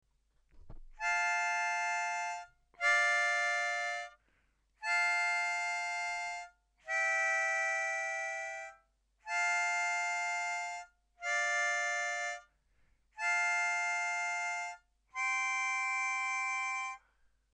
Let’s check out the Chord accompaniment.